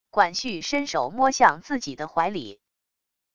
管绪伸手摸向自己的怀里wav音频生成系统WAV Audio Player